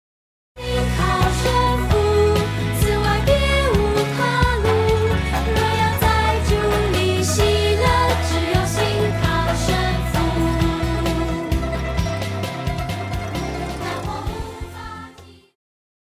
Christian
Pop chorus,Children Voice
Band
Hymn,POP,Christian Music
Voice with accompaniment